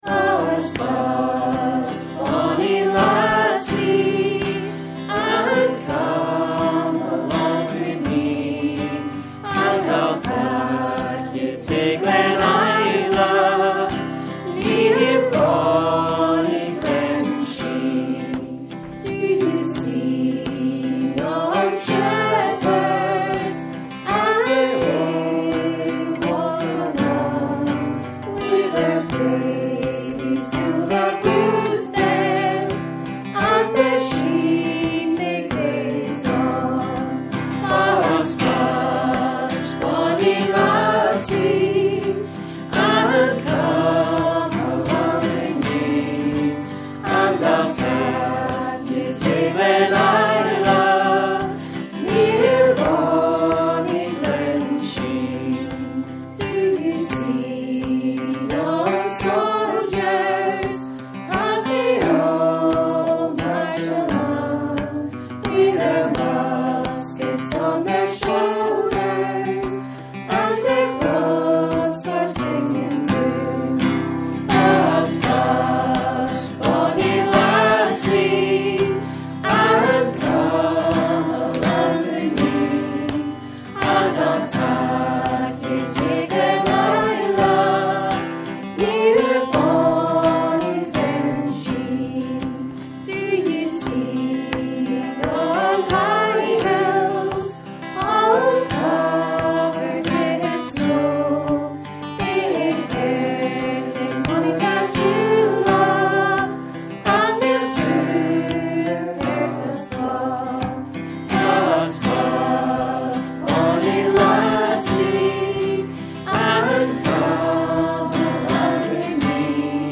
Also known as ' Bonnie Glen Shee,' this song is an old folk song from the Strathmore area of Perthshire. It’s tune was used for the well-known WW1 pipe tune ‘The Bloody Fields of Flanders.’